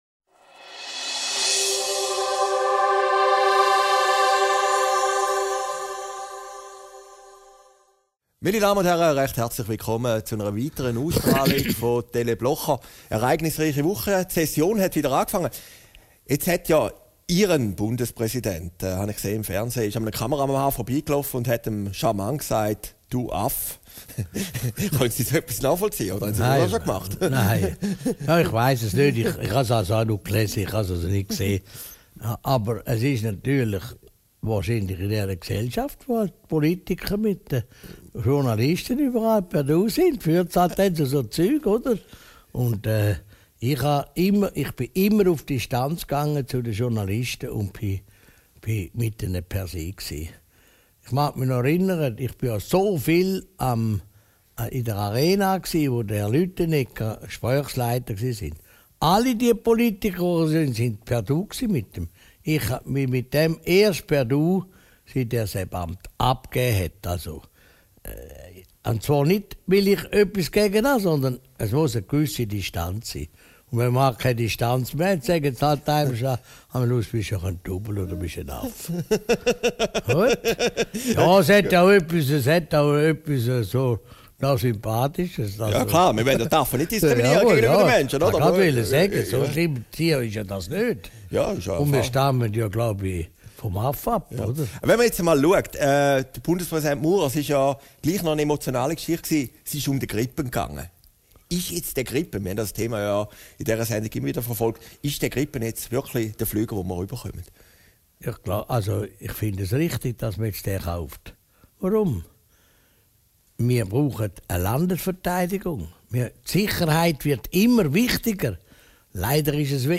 Video downloaden MP3 downloaden Christoph Blocher über Ueli Maurers Schimpftriade, den Gripen und den Bankendeal mit der SP Aufgezeichnet in Herrliberg, 13.